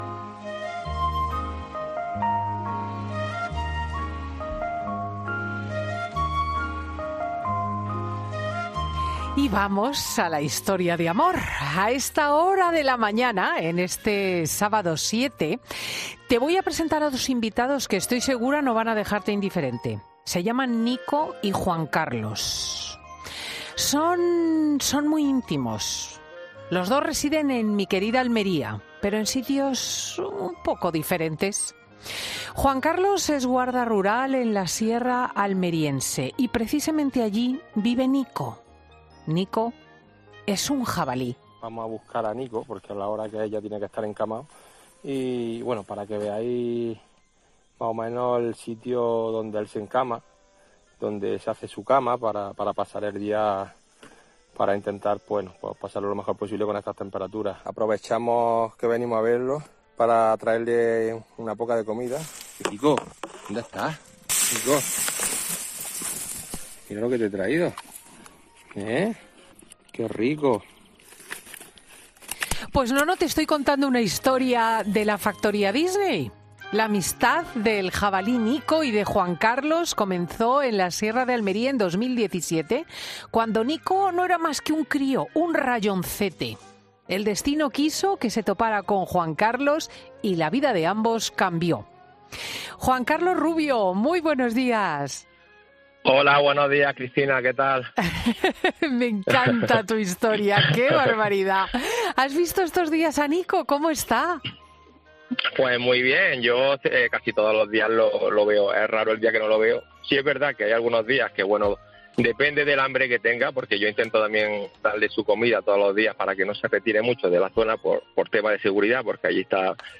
A esta hora de la mañana, te voy a presentar a dos invitados que, estoy segura, no van a dejarte indiferente.